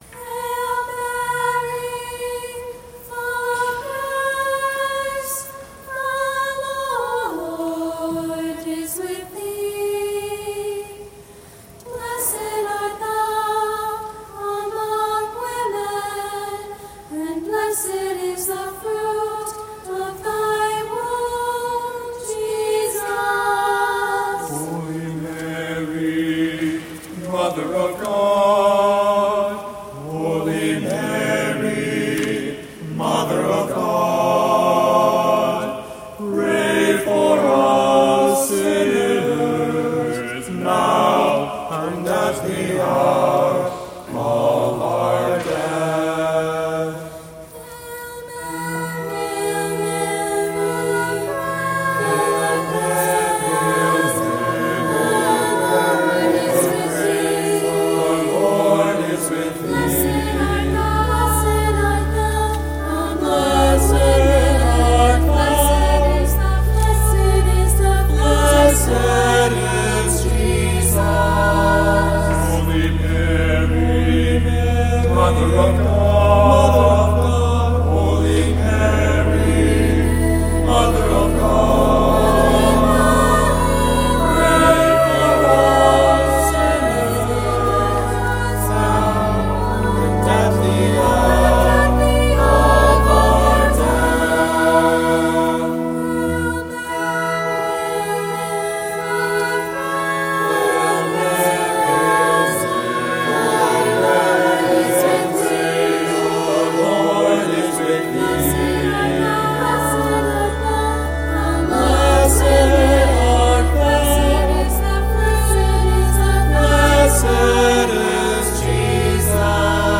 written for SATB Chorus & Organ
“Hail Mary” – Performed by Ave Maria University Schola Singers
World Premiere: March 22nd 2025, Composed for the Feast of the Annunciation Mass at Ave Maria Parish.
From a choral perspective, it sounds like a community of devout Catholics praying to Our Lady together.